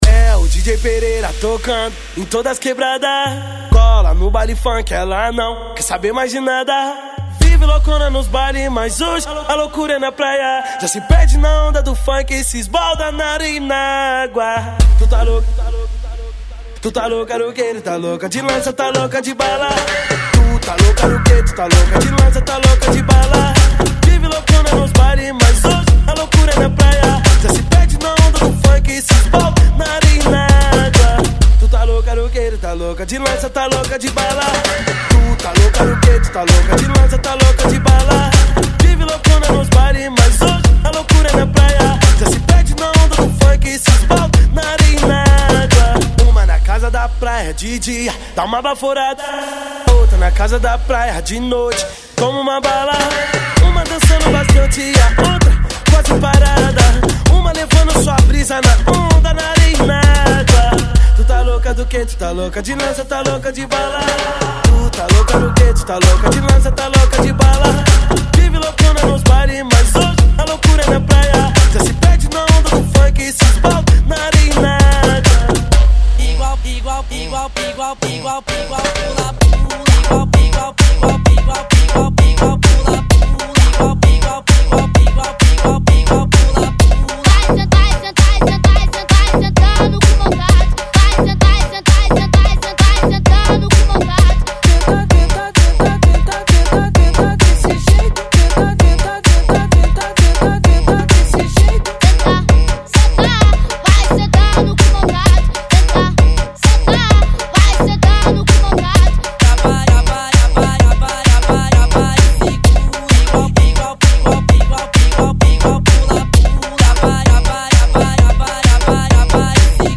Eletro Funk, Musica Eletronica